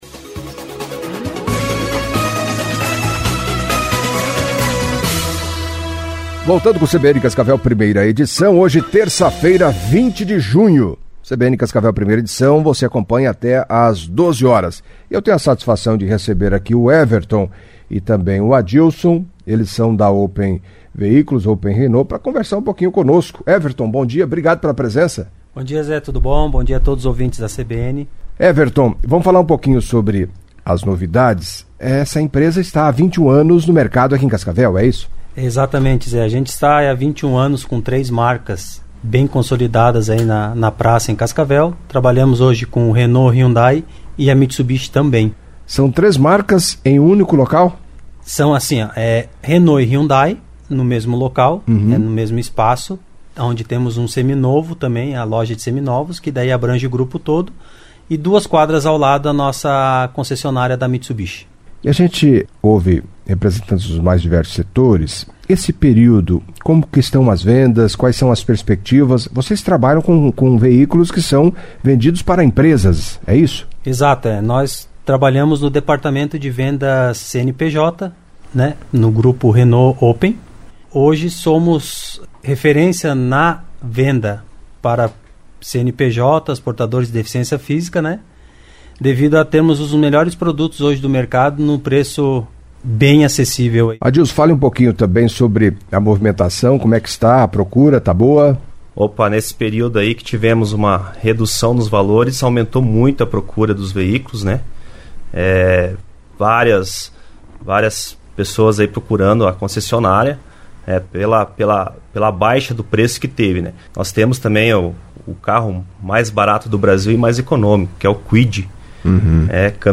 Em entrevista à CBN Cascavel nesta terça-feira (20)